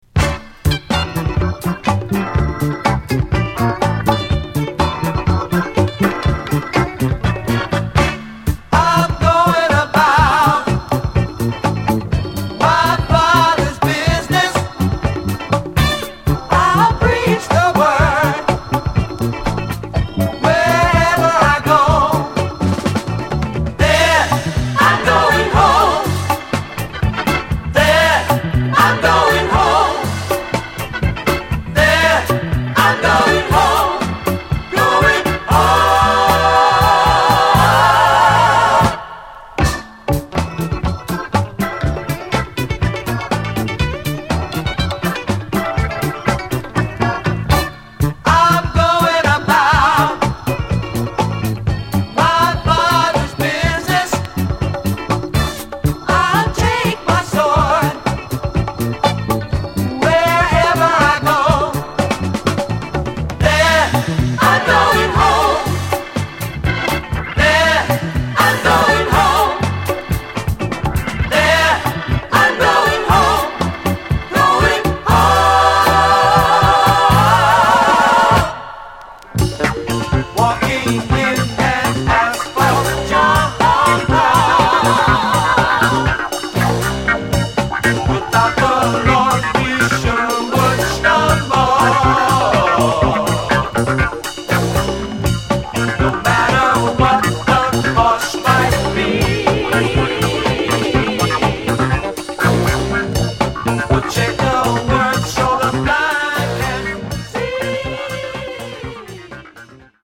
USのソウル＆ゴスペル・グループ